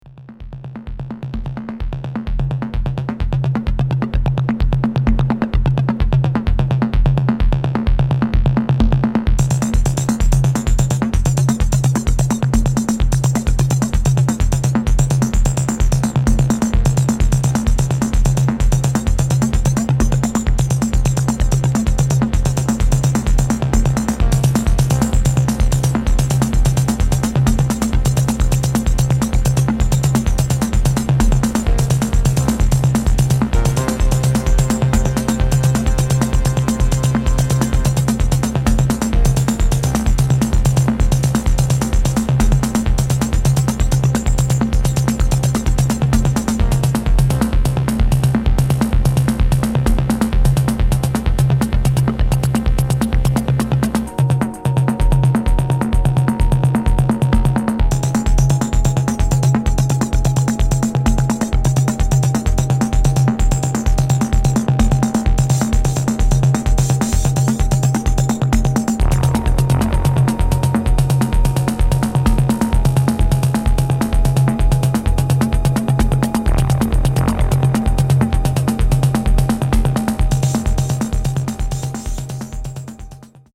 Electro Techno